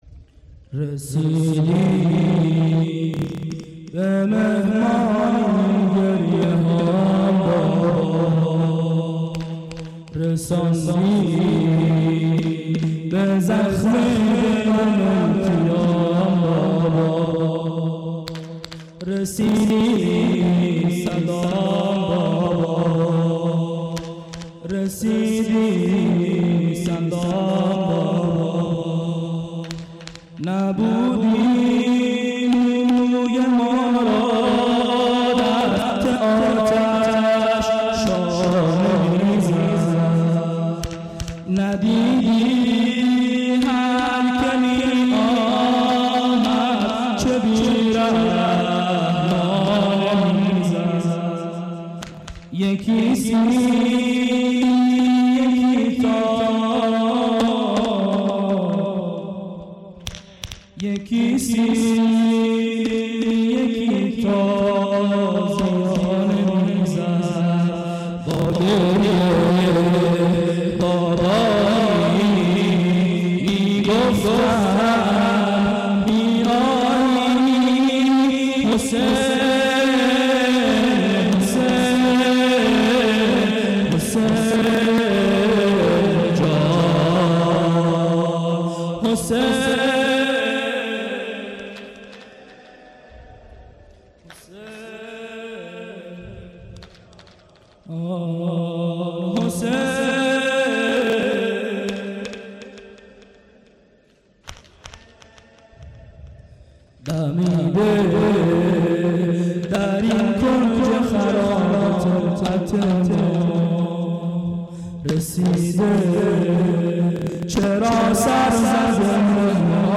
شب سوم محرم98 هیئت میثاق الحسین (ع) سیستان